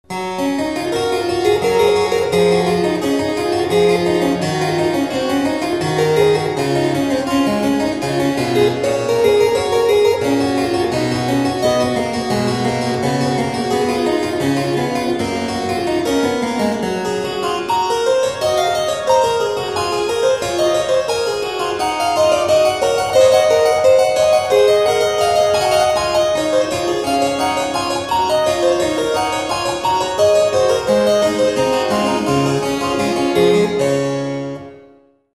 Baroque, Classical, Keyboard